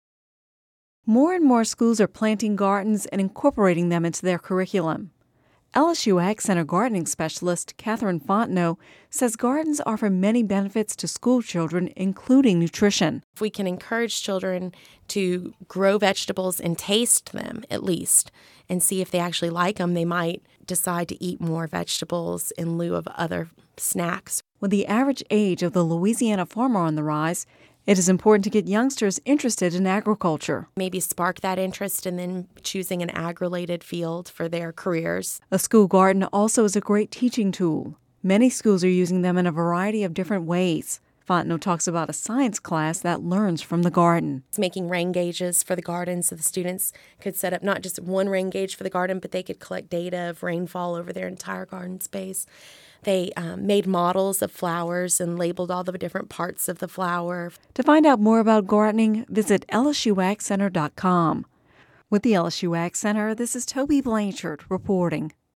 (Radio News 11/29/10) More schools are planting gardens and incorporating them into the curriculum.